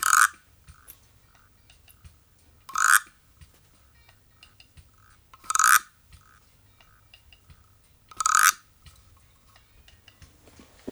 88-SCRAPER1.wav